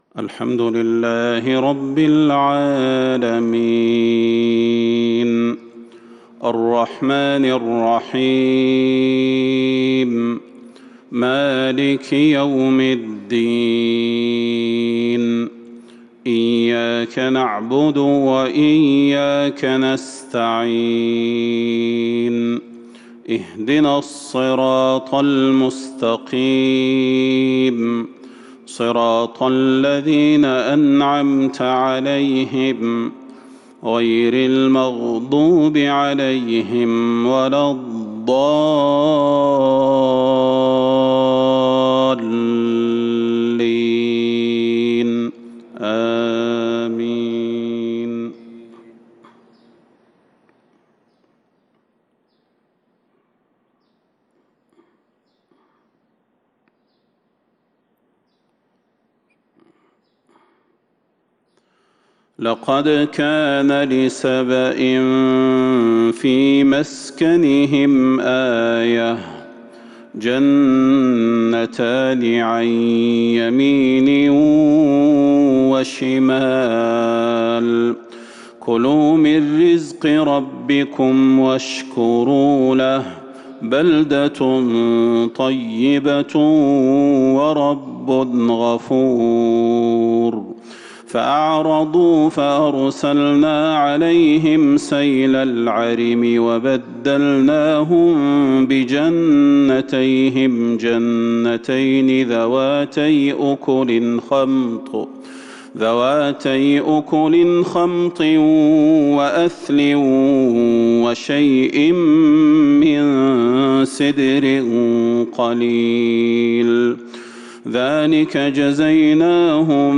صلاة الفجر من سورة سبأ | السبت 23 صفر 1442 |  Fajr Prayar from Surah saba | 10/10/2020 > 1442 🕌 > الفروض - تلاوات الحرمين